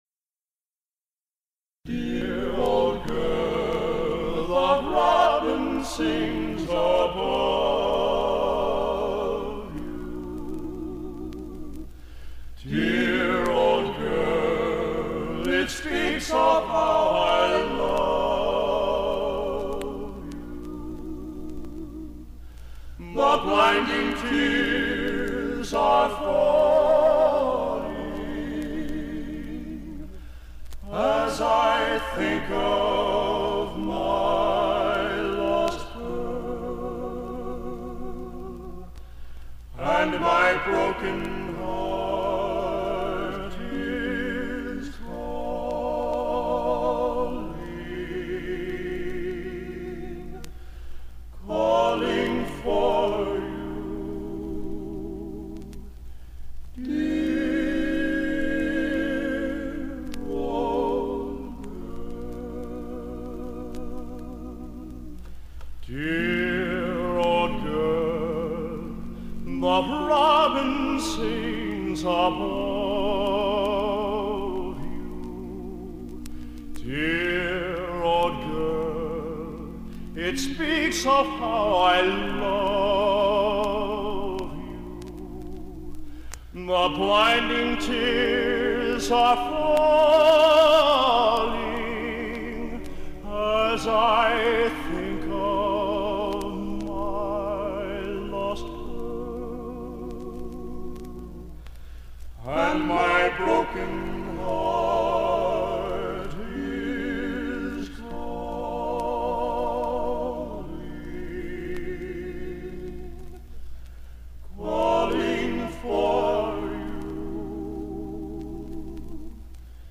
Genre: | Type: Studio Recording